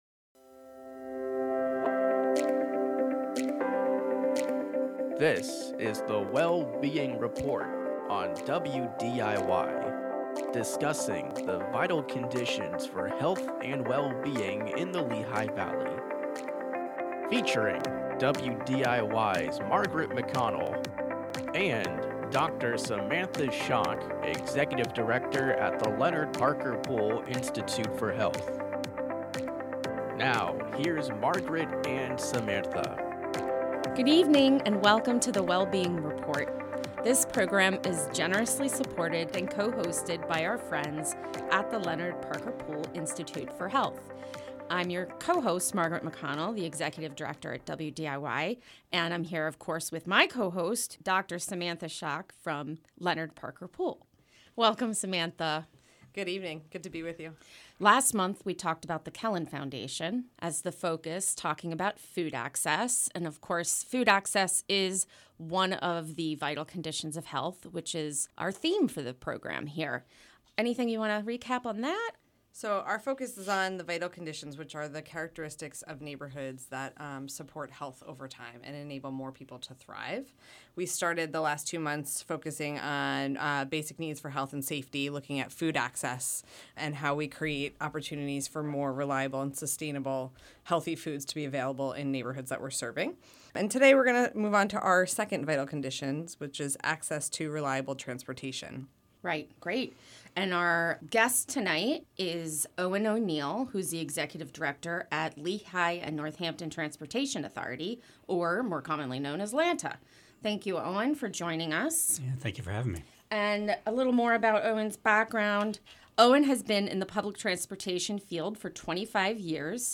monthly roundtable discussions